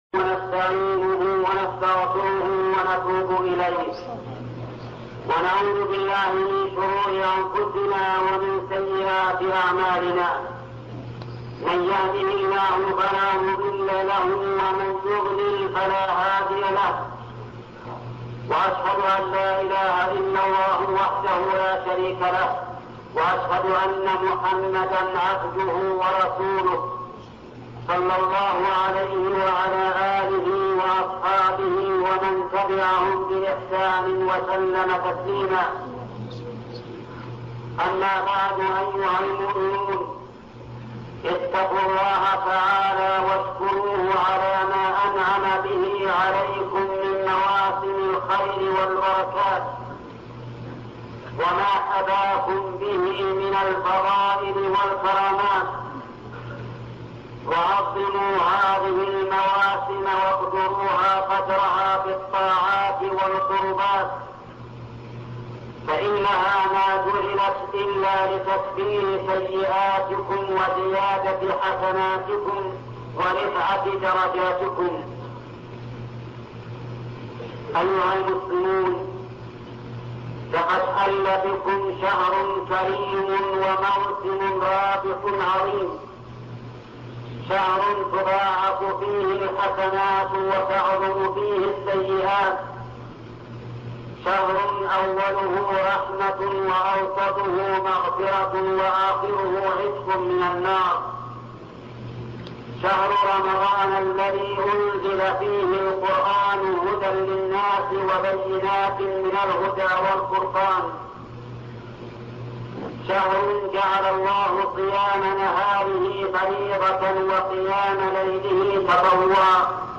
خطب الجمعة - الشيخ محمد بن صالح العثيمين